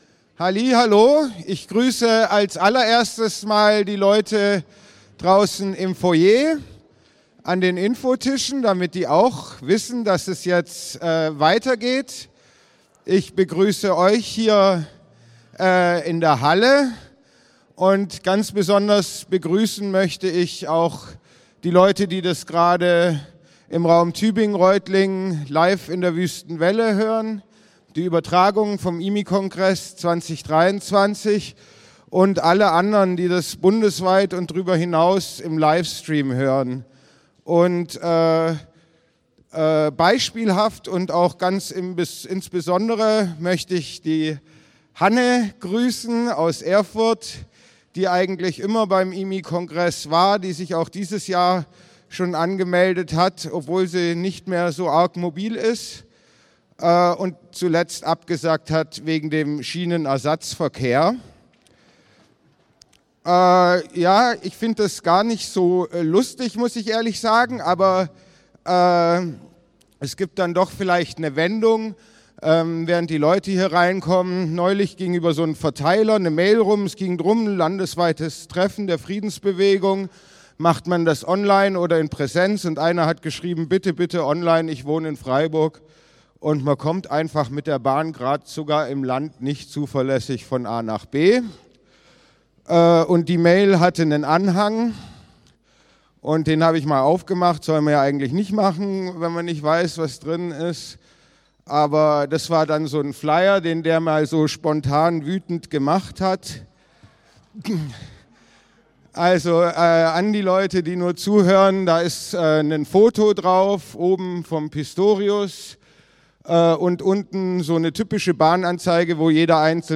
Grußworte Panel: